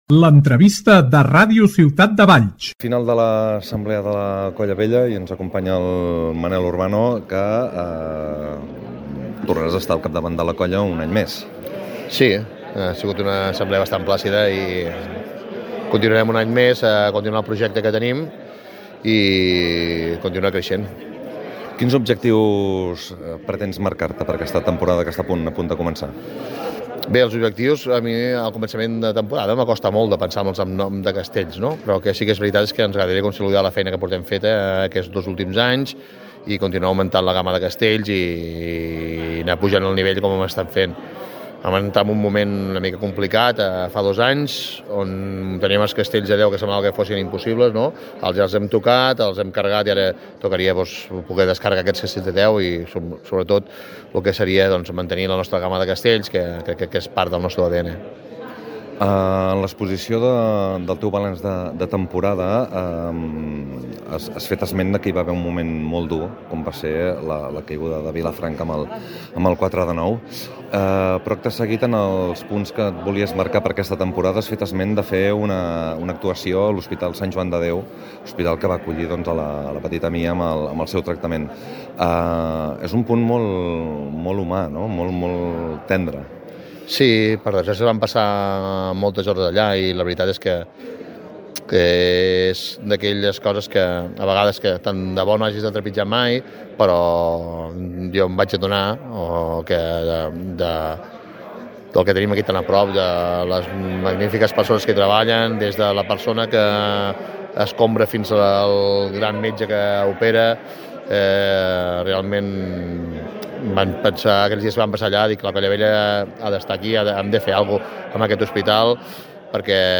Nova entrevista a Ràdio Ciutat de Valls.